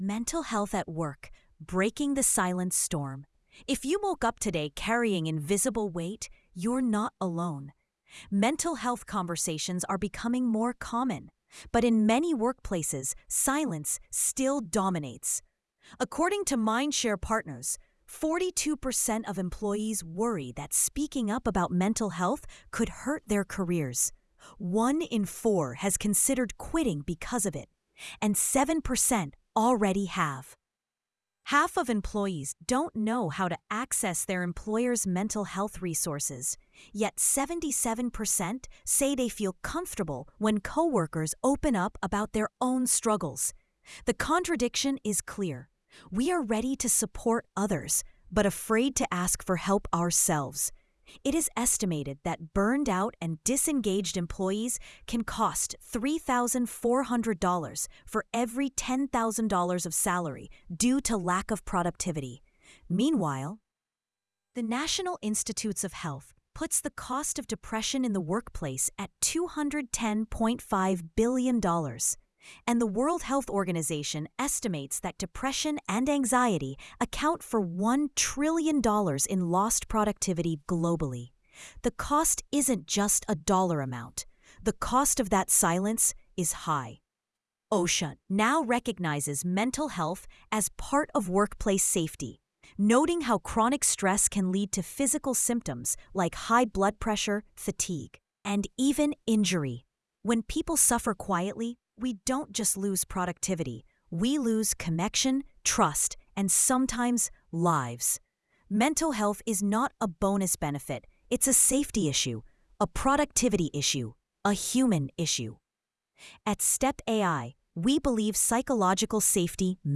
sage_gpt-4o-mini-tts_1x_2025-06-23T22_39_40-578Z.wav